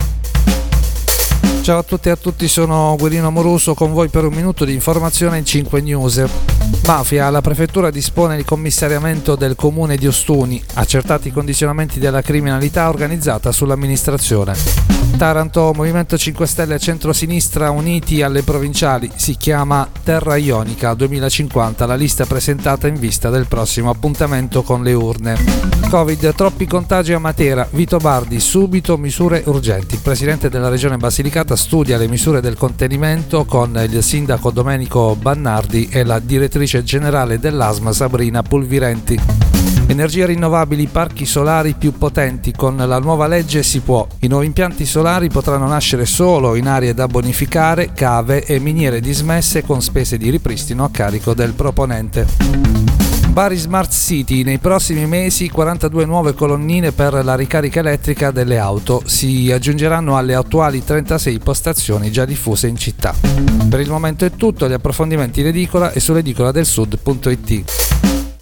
Giornale radio alle ore 19.